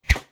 Close Combat Swing Sound 61.wav